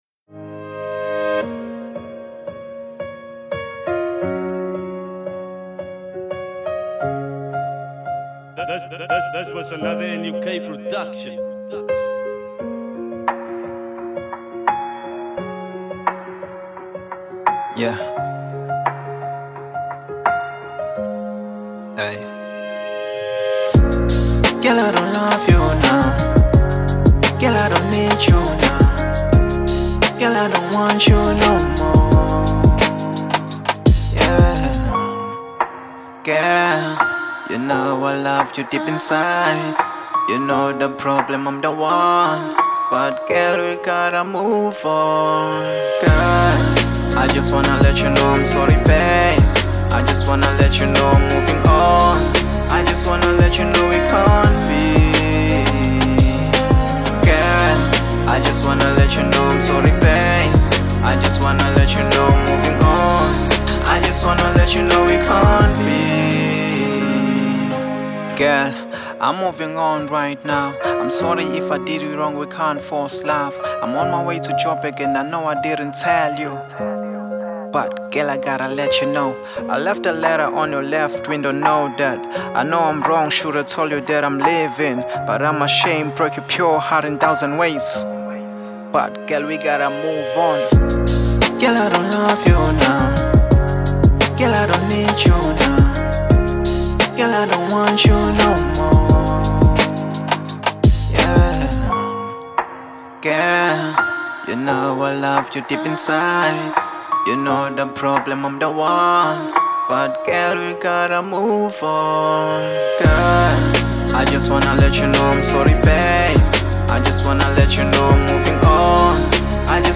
02:52 Genre : Hip Hop Size